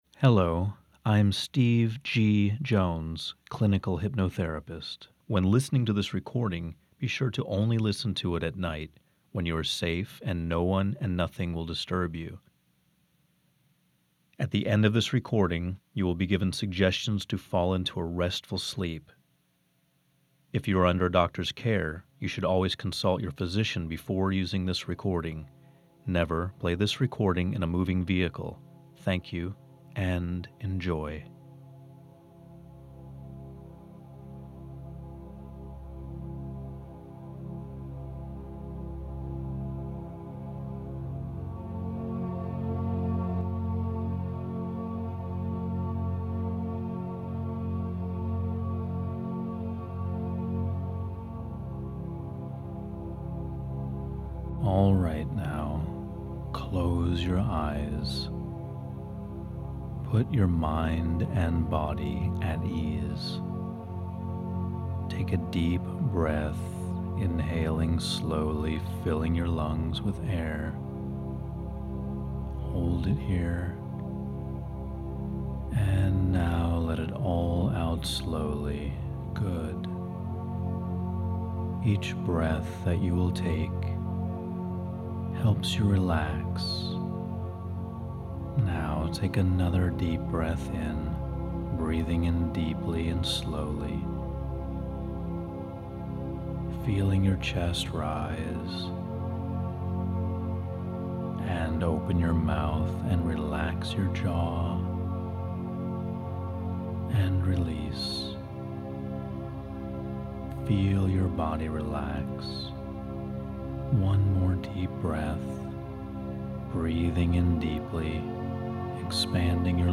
HYPNOTIC INTENSIVE AUDIO
HYPNOSIS+-+AM+Module+17+-+Entrepreneurial+Mind.mp3